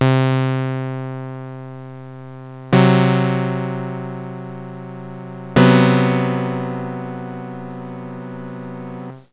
C, F# then B are played; held notes come back at full volume.
Three overlapping notes (C, F# then B) are played on a Korg Volca Keys. Previous notes that had fallen to their "sustain" level come back at full volume when the second and third are played.
Korg_Volca_Keys_paraphonic_behaviour.flac